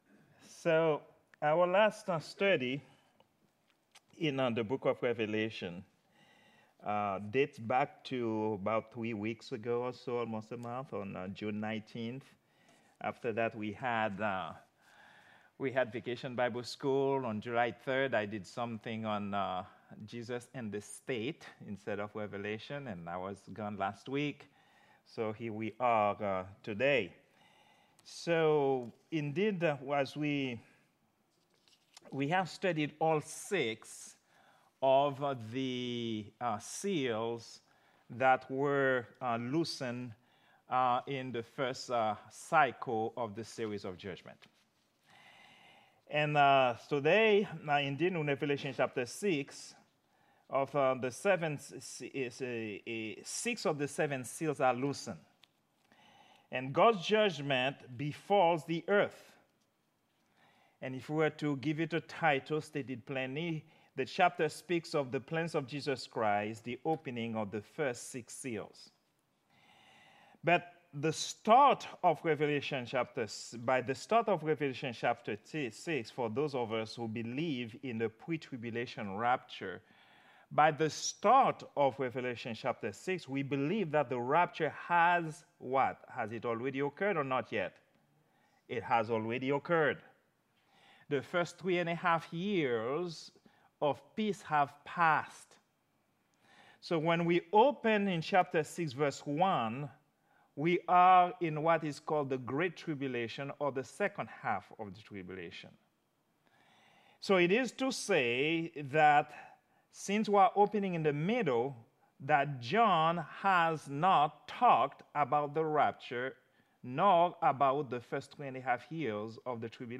Prayer_Meeting_07_17_2024.mp3